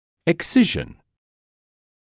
[ɪk’sɪʒn][ик’сижн]иссечение, эксцизия